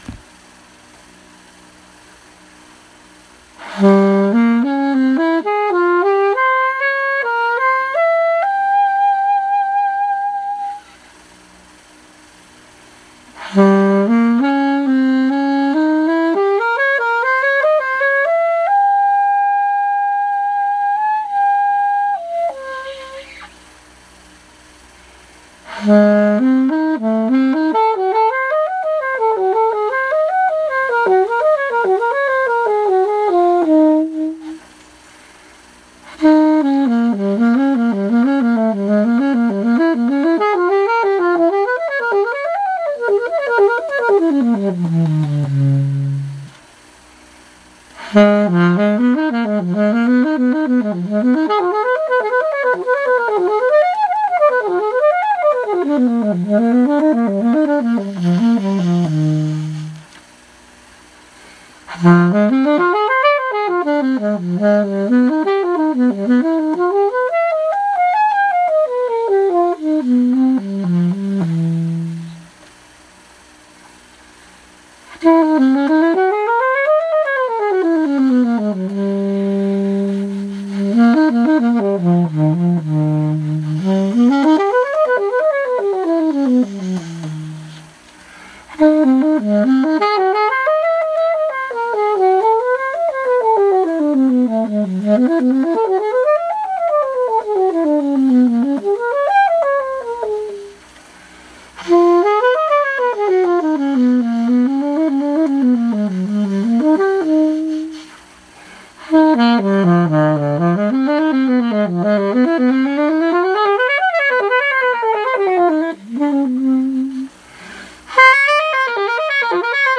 Saxophone
Recorded 11th August 2016, Madrid.